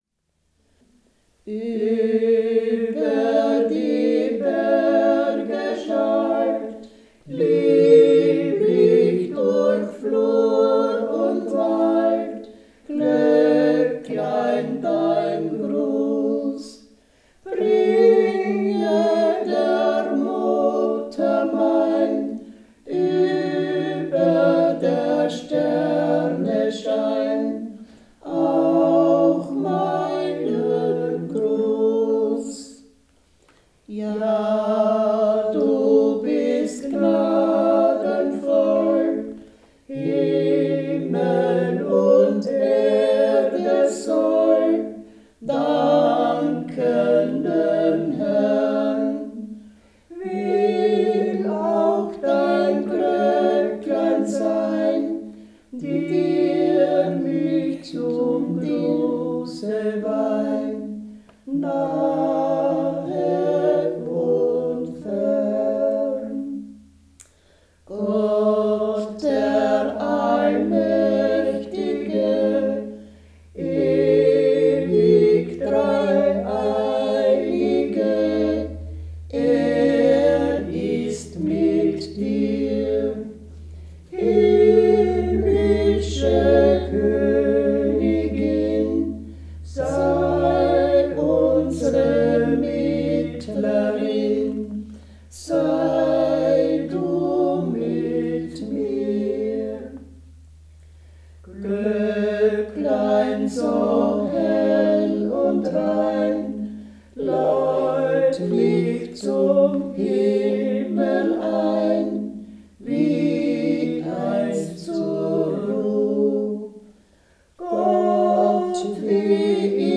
Die „Leichhüatlieder“, welche zwei Nächte lang im Hause eines Verstorbenen vor dem aufgebahrten Toten gesungen wurden. 192 Lieder mit Text- und Melodievarianten, Melodienincipits, 3 CDs mit historischen Tonaufzeichnungen, Wörterbuch des lokalen Dialekts.
Traditional music of the Wechsel, styrian-lower austrian border region, 100 kms south of Vienna. Volume 1 „The religious song“ sung during the farmer’s traditional two-night corpse-watch at the bier in the house of the deceased. 192 songs with text, music and incipits, 3 CDs with historical recordings, dictionary of local dialect.
Traditional music in the Styrian / Lower Austrian Wechsel-region; songs during the corpse-watch in the farmhouse of the deceased, CDs, historical recordings, dictionary of local dialect; incipits
Church music
Folk & traditional music